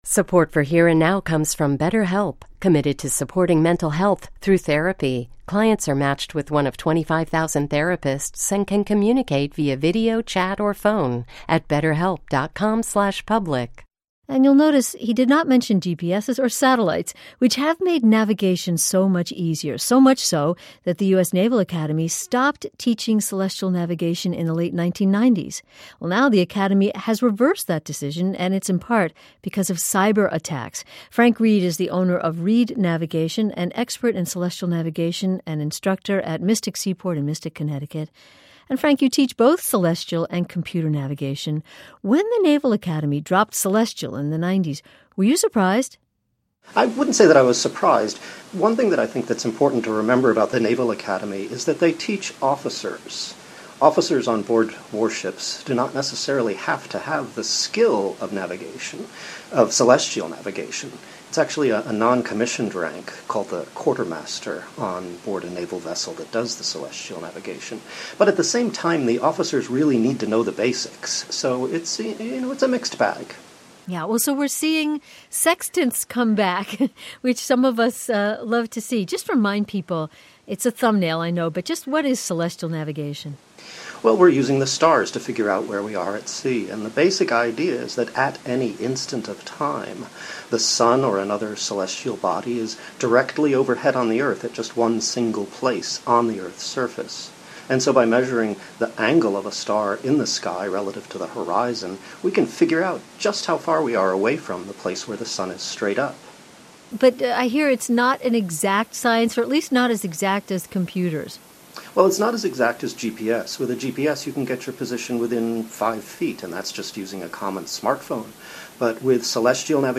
NPR-naval-academy-celestial-interview-oct-2015.mp3